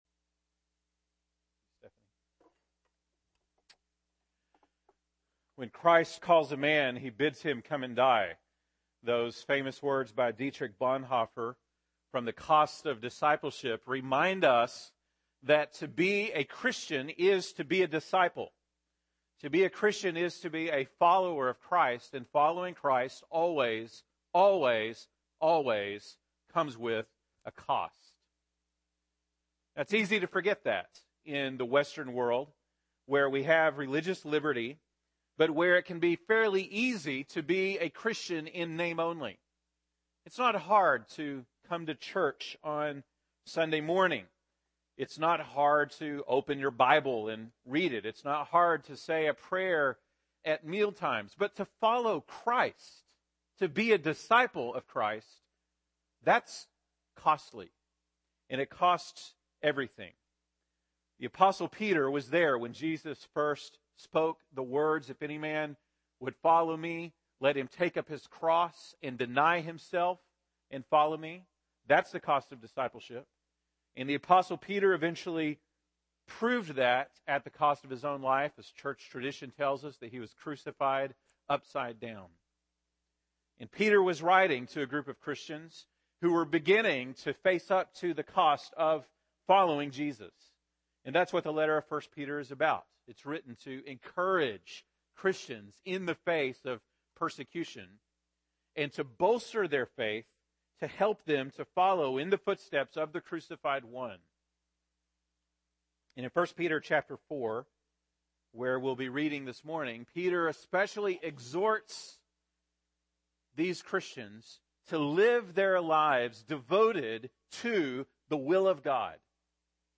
October 18, 2015 (Sunday Morning)